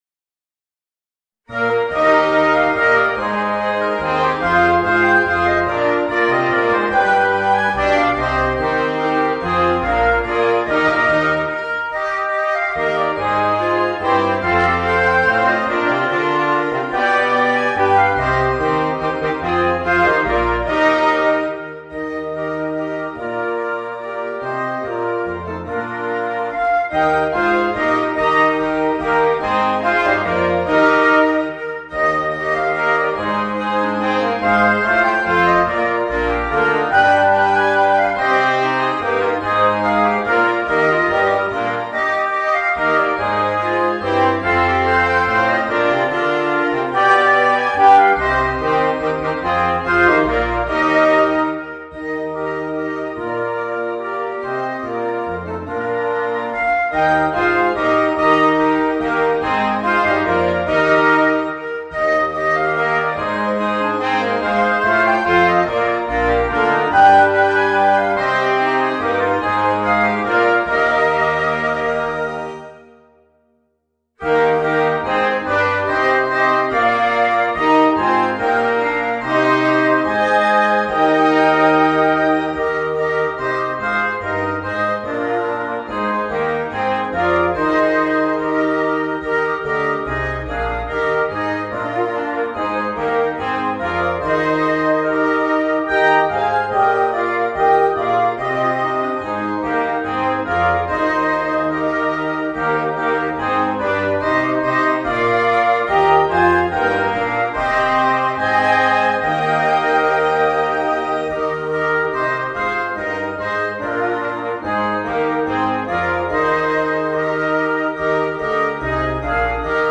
Gattung: für Holzbläserquintett
Flöte, Oboe, Klarinette, Horn in F, Fagott